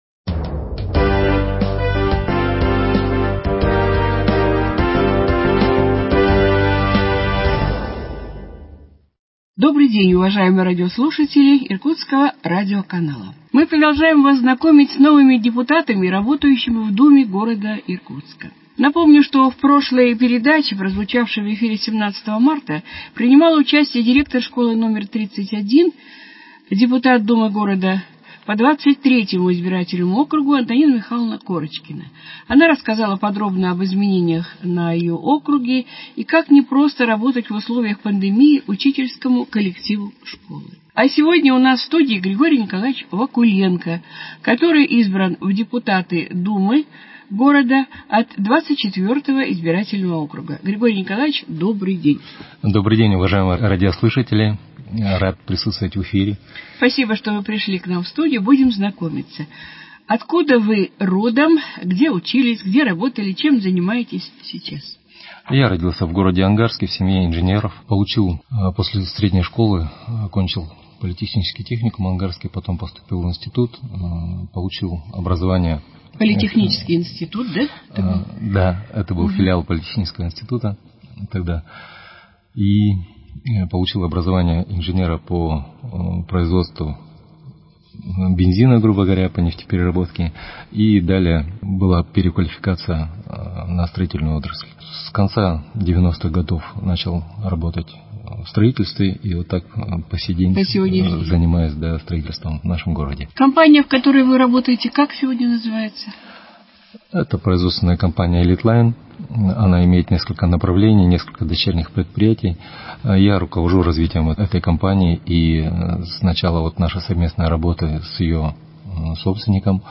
С депутатом Думы Иркутска по 24-му избирательному округу Григорием Вакуленко беседует